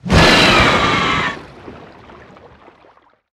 Sfx_creature_snowstalkerbaby_death_swim_01.ogg